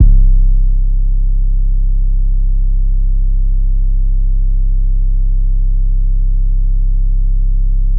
Long Spinz 808.wav